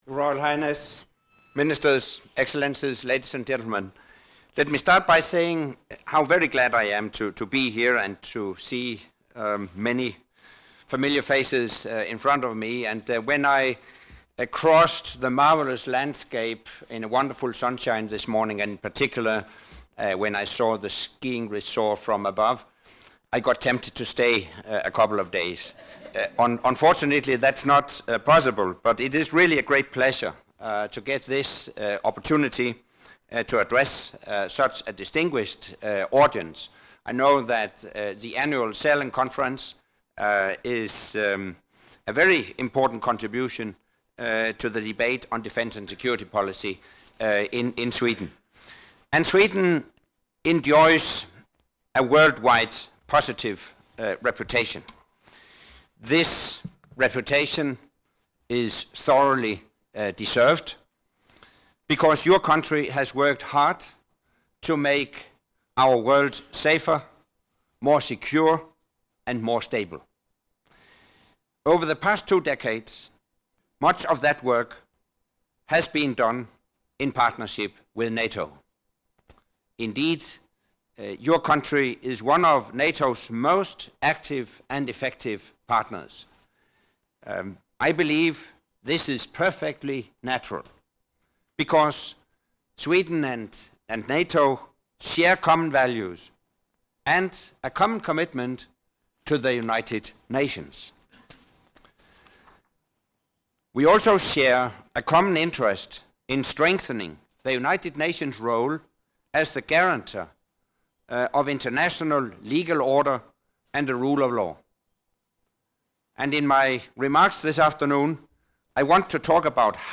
NATO and Sweden: Strong Partners in support of the United Nations - Speech by NATO Secretary General Anders Fogh Rasmussen in Sälen, Sweden
From the event Secretary General in Sweden to build on strong partnership 14 Jan. 2013 Audio Speech by NATO Secretary General Anders Fogh Rasmussen in Sälen, Sweden (including Q&A session) 15 Jan. 2013 | download mp3 Topics The Partnership for Peace programme NATO’s relations with Sweden